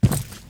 FootstepHeavy_Concrete 03.wav